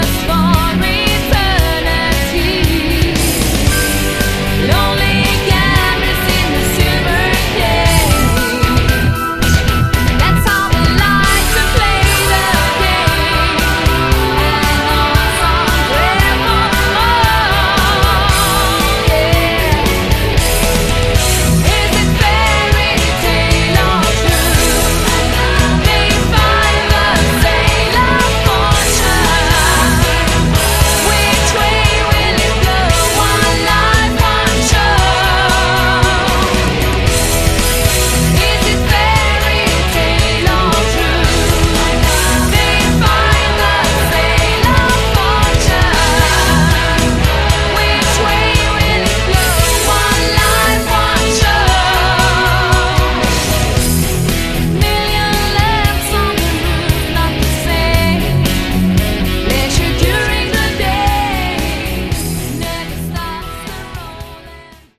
Category: AOR/Melodic Rock
Vocals
Guitars
Bass
Drums, Keyboards